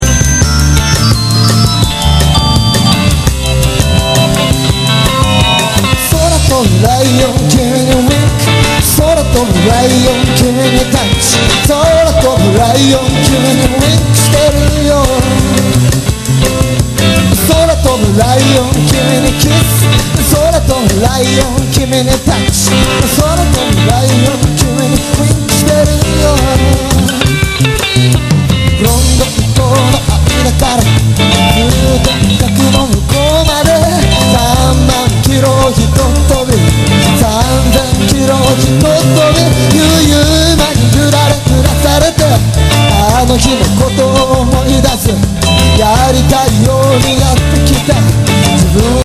前作とはかなり様相を変え、ハウスやラウンジを強く意識した内容になっている。
全曲にポップな歌がフューチャーされている。